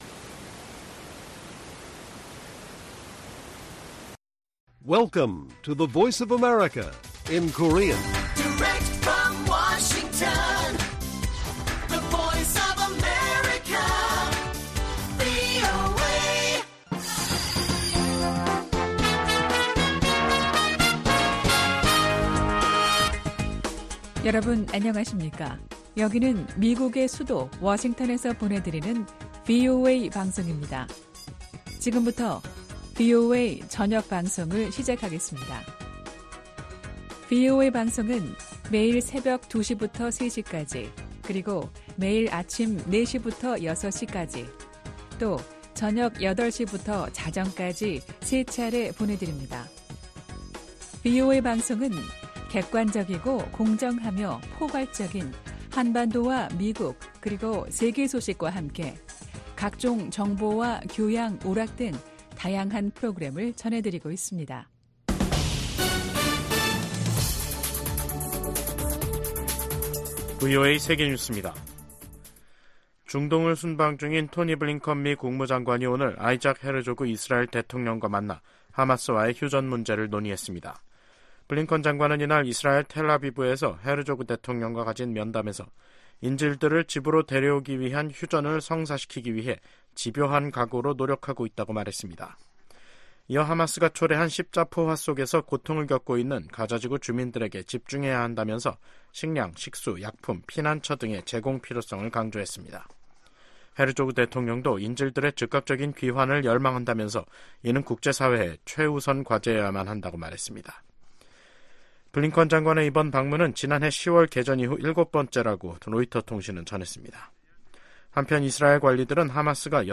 VOA 한국어 간판 뉴스 프로그램 '뉴스 투데이', 2024년 5월 1일 1부 방송입니다.